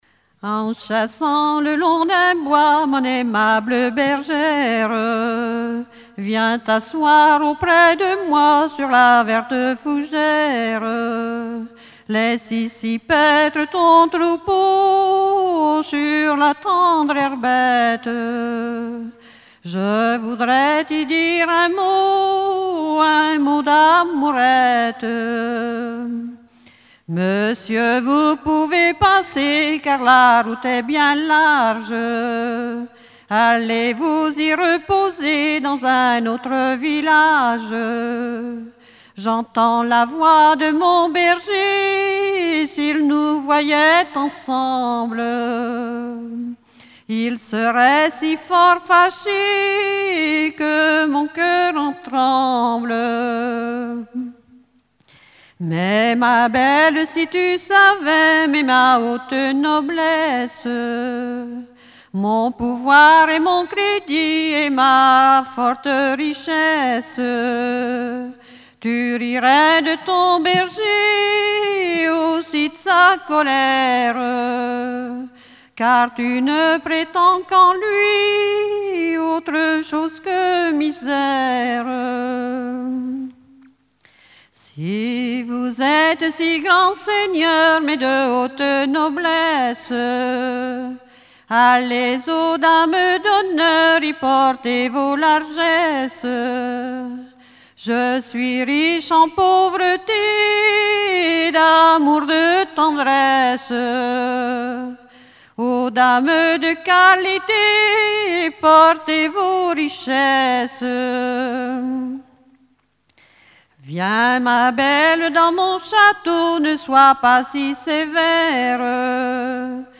Genre strophique
Festival de la chanson traditionnelle - chanteurs des cantons de Vendée
Pièce musicale inédite